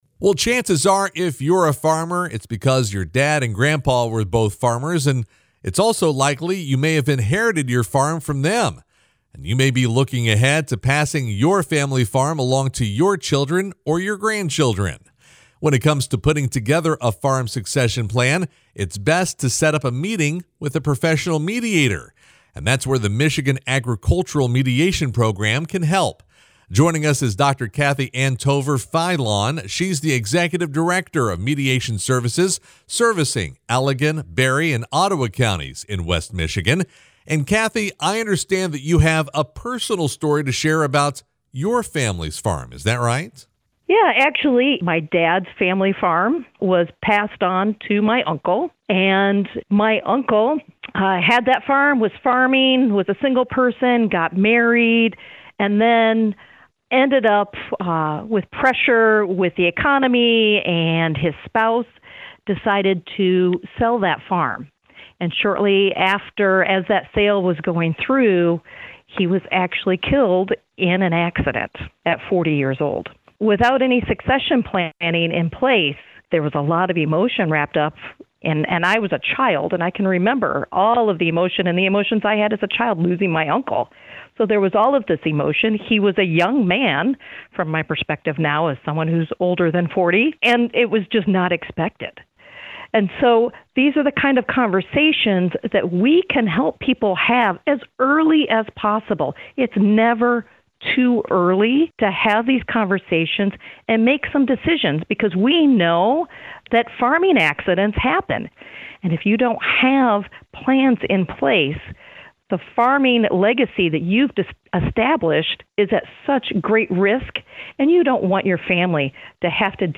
full conversation